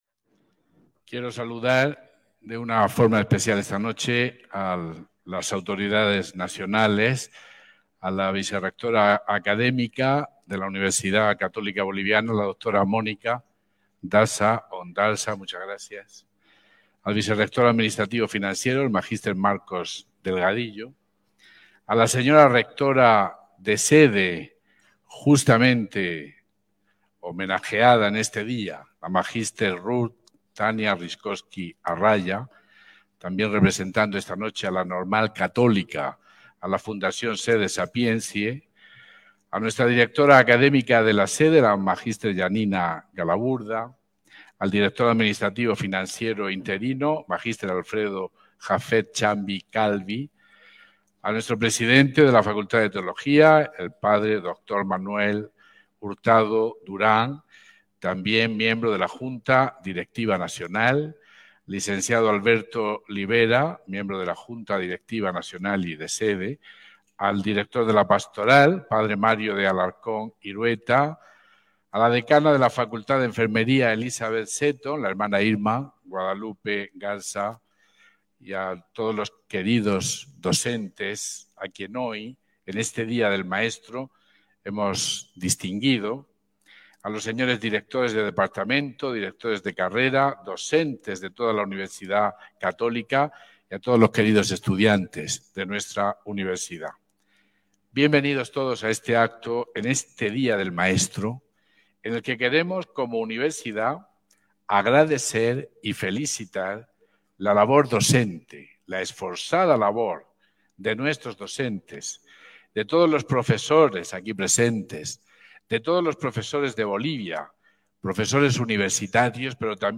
RN 07.06.2023. El 6 de junio de 2023 se realizó en Sede Cochabamba UCB el homenaje por el Día del Maestro, reconociendo la labor educativa de los docentes destacados de esta casa superior de estudios.